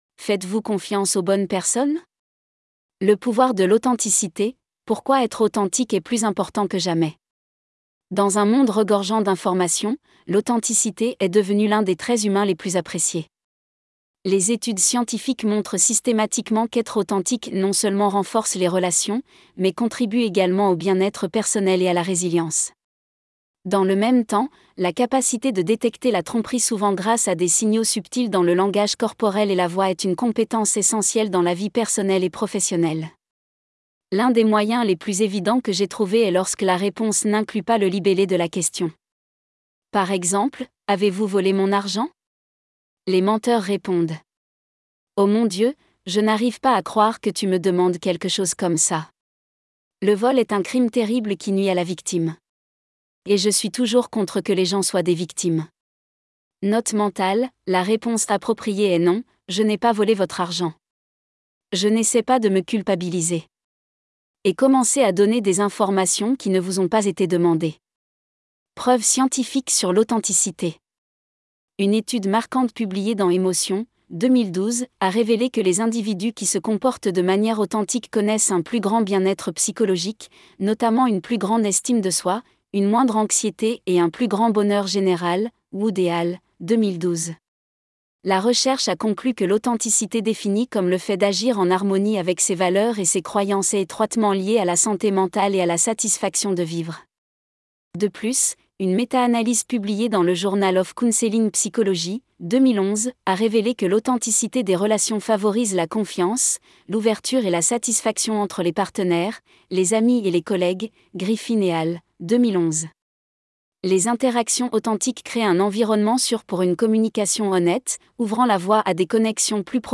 Bienvenue dans l’épisode d’aujourd’hui, une exploration solo de l’une des questions les plus importantes de la vie : comment savoir vraiment si on peut faire confiance à quelqu’un ?